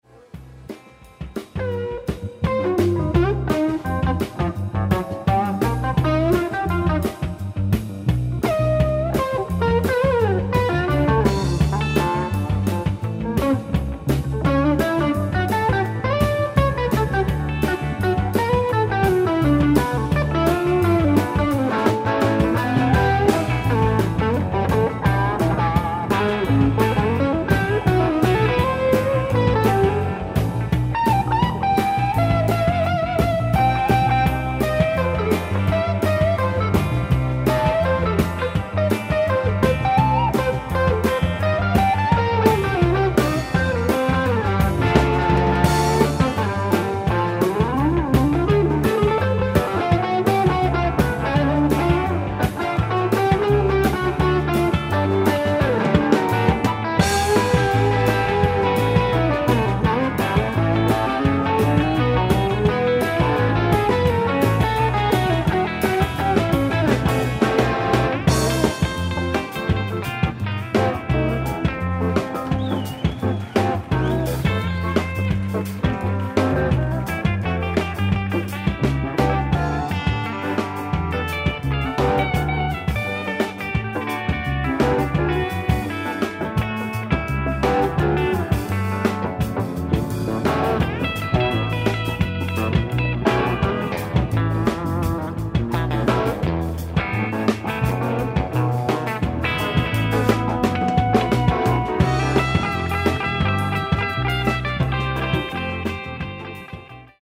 ライブ・アット・青少年センター、アムベルク・フィデル、ミュンスター、ドイツ 02/20/1987
オフィシャル級のデジタル放送音源！！
※試聴用に実際より音質を落としています。